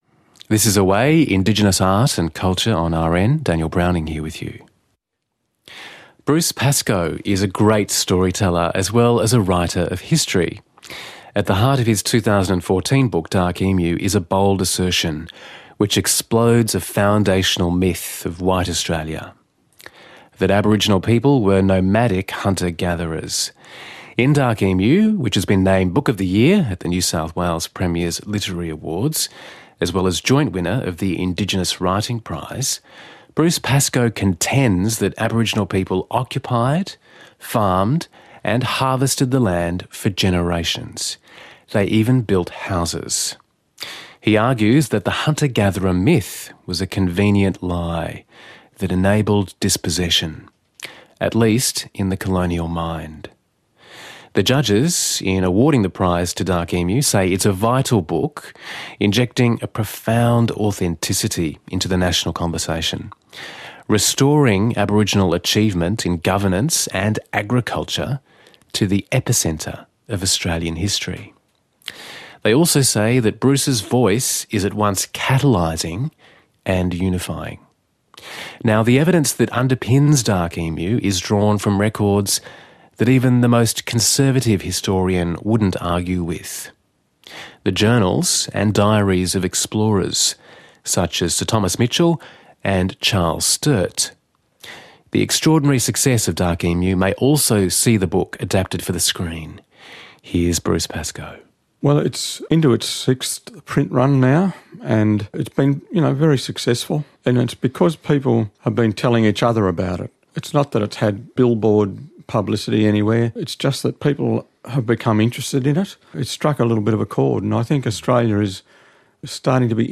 Bruce tells the AWAYE program on ABC RN that he is currently working on the screen writing of a film relating to Dark Emu/